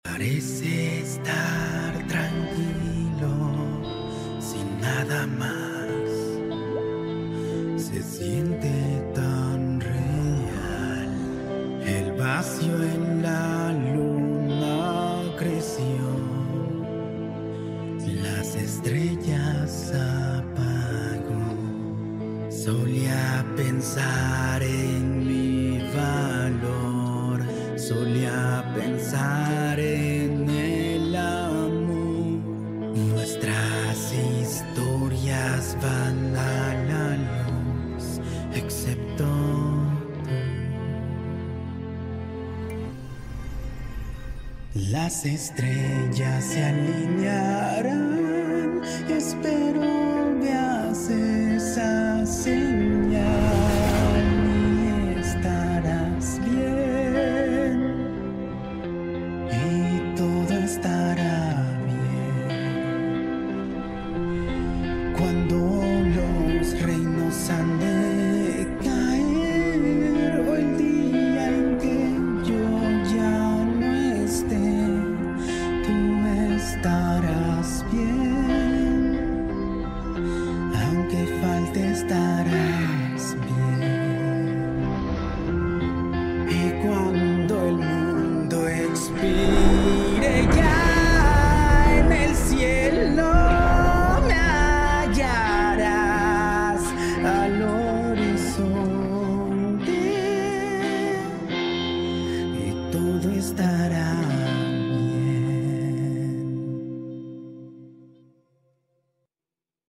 Cover en Español latino